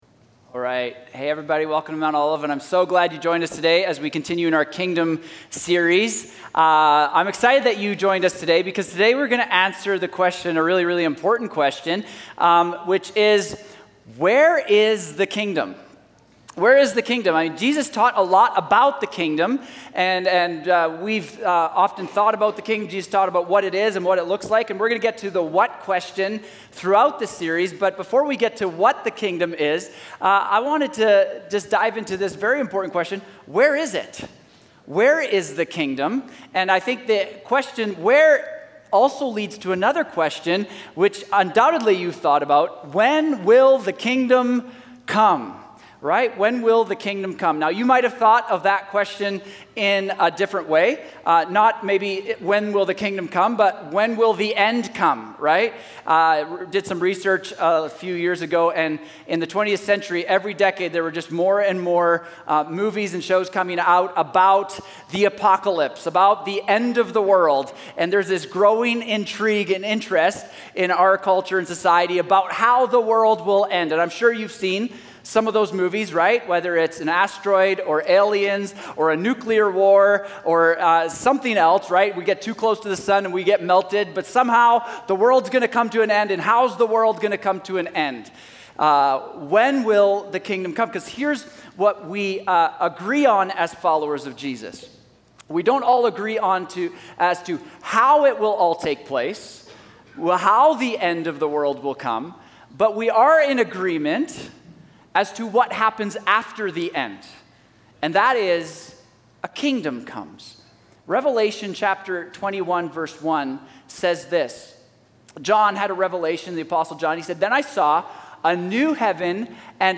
Sermons | Mount Olive Church